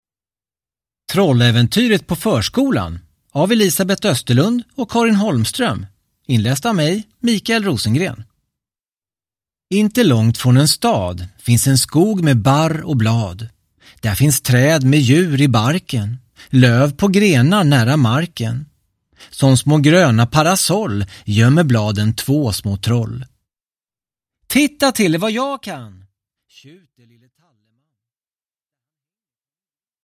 Trolläventyret på förskolan (ljudbok) av Elisabet Österlund